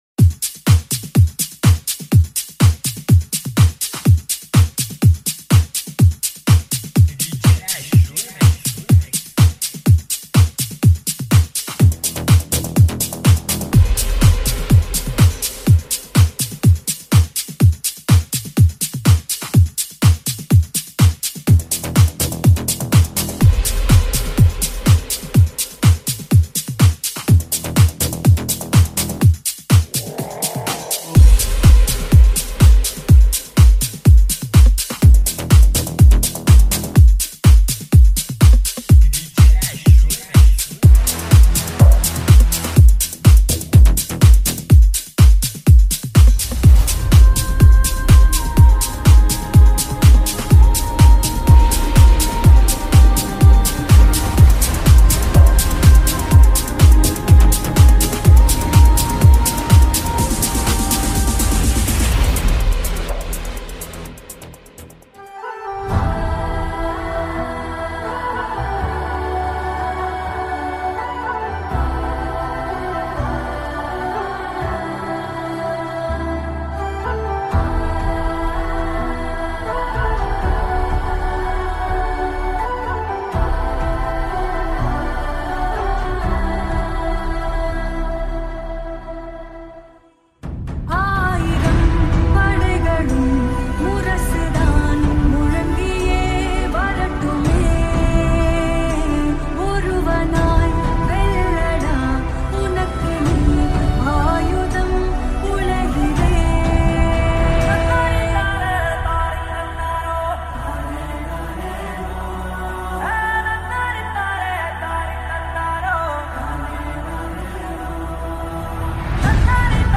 High quality Sri Lankan remix MP3 (3.4).